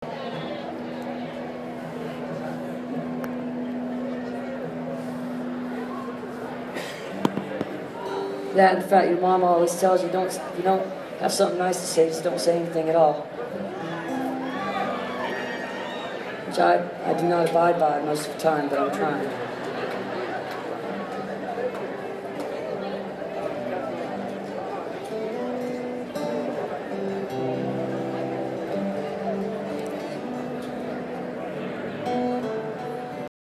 lifeblood: bootlegs: 2016-09-23: thunder valley casino resort - lincoln, california (opening for cyndi lauper)
01. talking with the crowd (0:33)